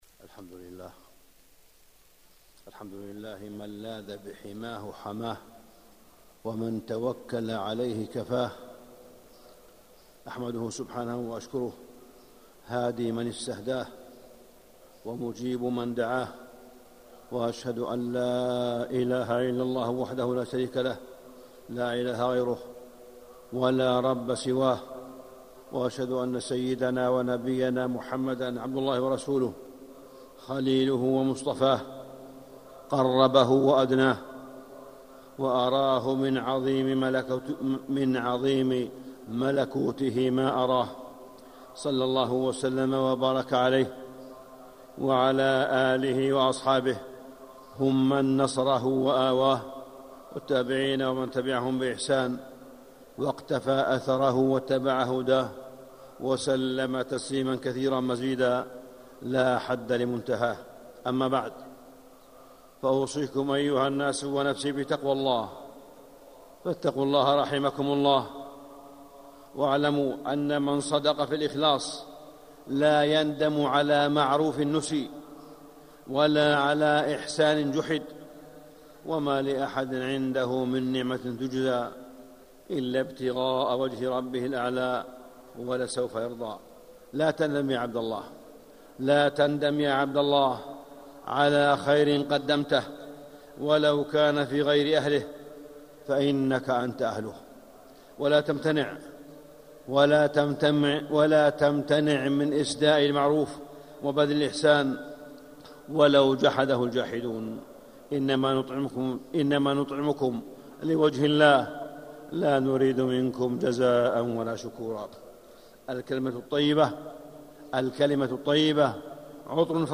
مكة: نعمة المعافاة والعافية - صالح بن عبد الله بن حميد (صوت - جودة عالية